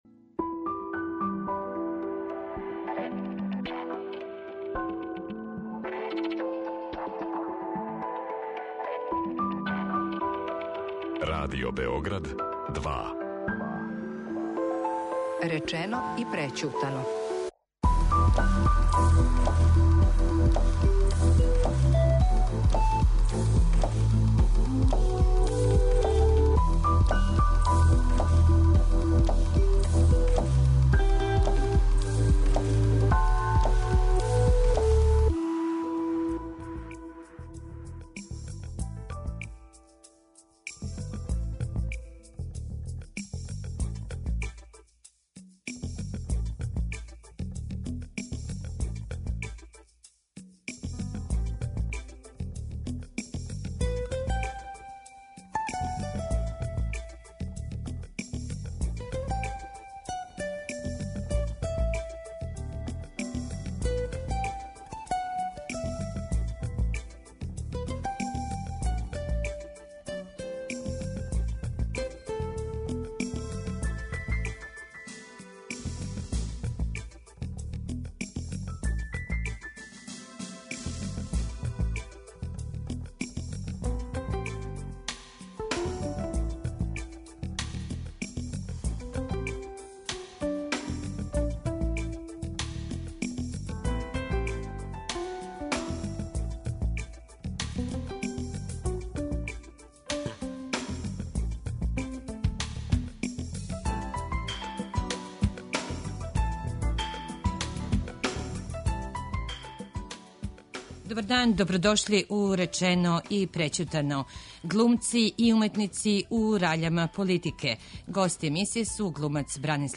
Гости су Бранислав Лечић, др Неле Карајлић и Светислав Буле Гонцић.